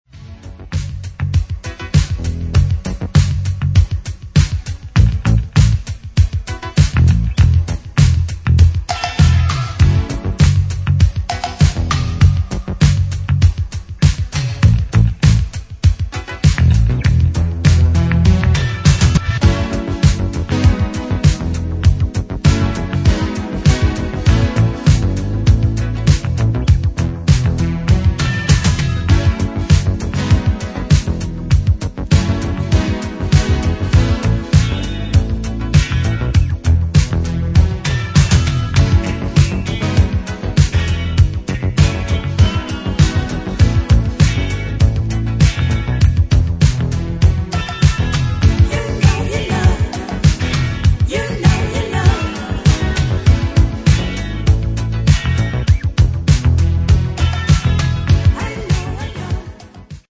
A journey into deep Disco, Italo electro and proto techno.